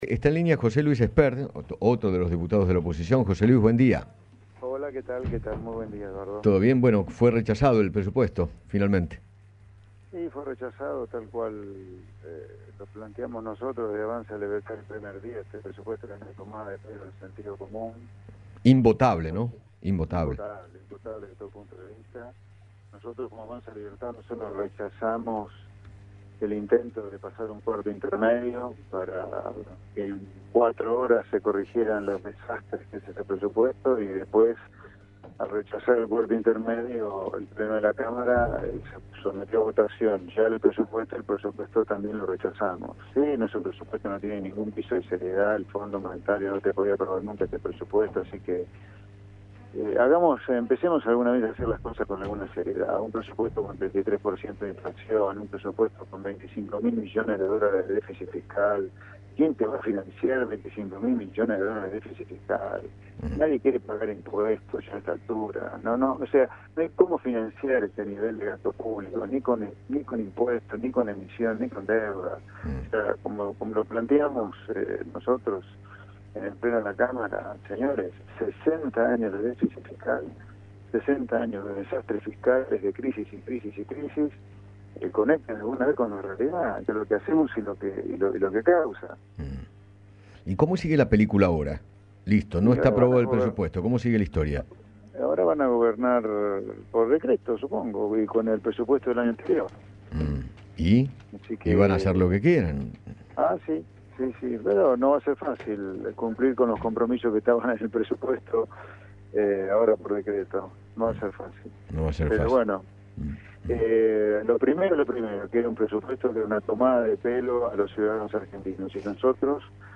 José Luis Espert, diputado nacional, habló con Eduardo Feinmann acerca del tenso debate por el Presupuesto 2022 que presentó el Gobierno y que fue rechazado por la Cámara Baja.